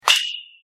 金属バットでボールを打つ - 着信音